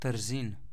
Trzin (pronounced [təɾˈzin]
or [təɾˈziːn]; German: Tersain[2]) is a town in the eastern part of the Upper Carniola region of Slovenia.